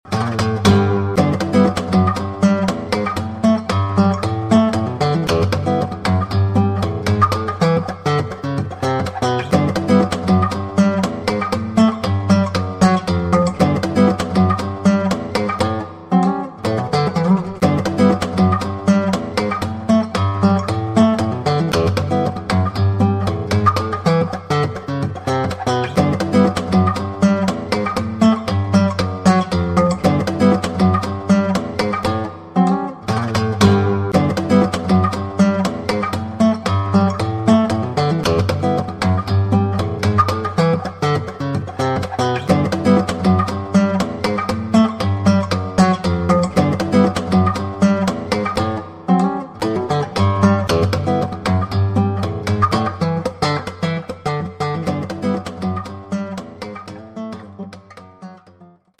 Funk Bass (On Guitar)
However, about 10 years ago I started to adapt what I had learned on bass to guitar, and believe it or not the classical nylon-string was perfect due to the snappiness of the bass strings and percussion possibilities.
"Classic Funk" is one such example, a two-chord vamp between Em and A7 using a combination of slapped open strings, left and right-hand mutes, octaves, power chords, upper-string chords, double-stops, trills, slurs, etc, etc. Just about a minute of unadulterated fun(k) on guitar.
classicfunk.mp3